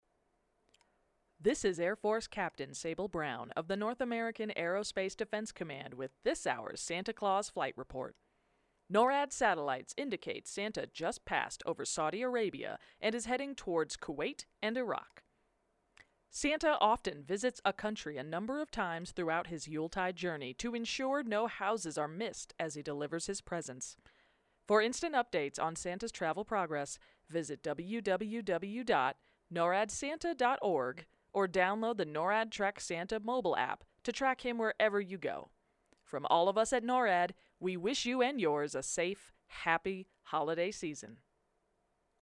NTS 12pm MTS Radio update